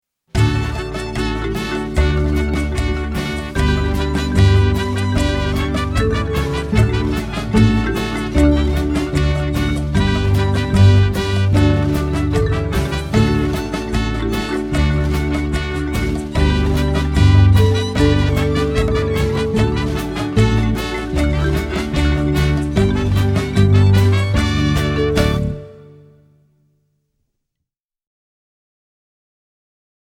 Voicing: String Bass w/c